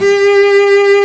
Index of /90_sSampleCDs/Roland LCDP13 String Sections/STR_Combos 1/CMB_Lrg Ensemble
STR SOLO C0W.wav